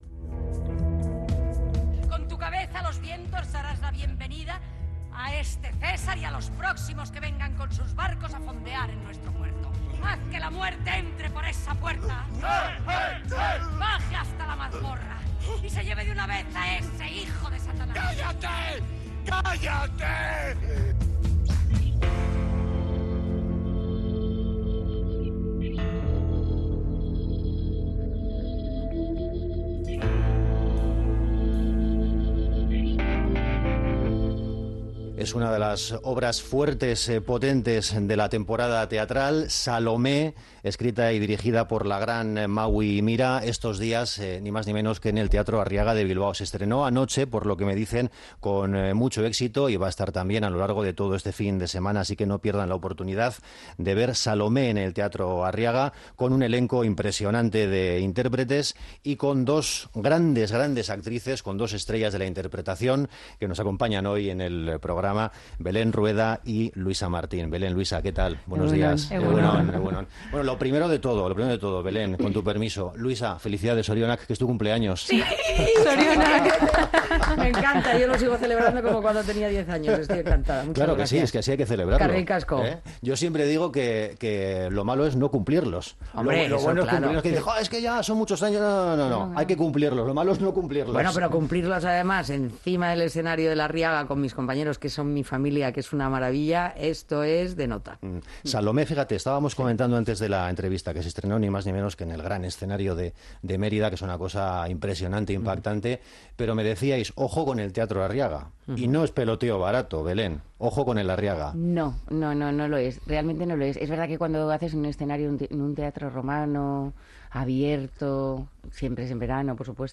Belén Rueda y Luisa Martín nos presentan "Salomé", hasta el domingo en el Teatro Arriaga - Onda Vasca
Morning show conectado a la calle y omnipresente en la red.